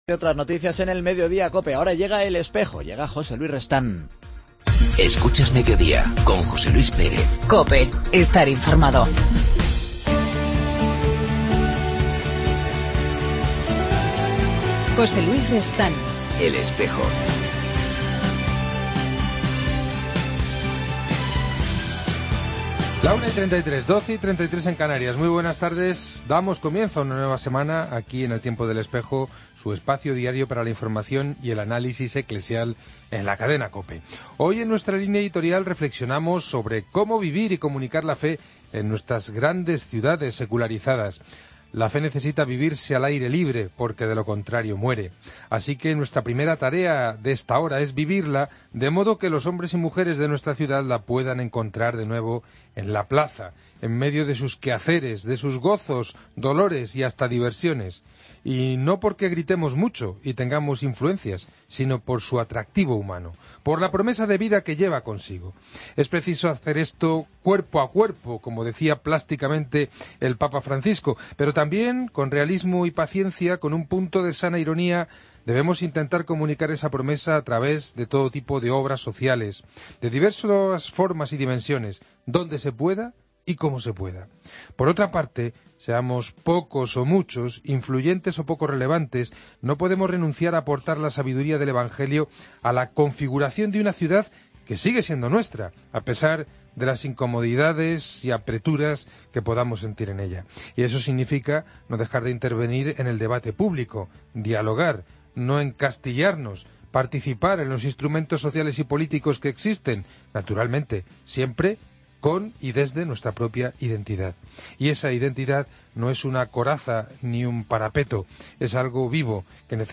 En El Espejo de hoy hemos entrevistado al recientemente nombrado Vicario General de la Prelatura del Opus Dei, el sacerdote argentino Mariano Fazio.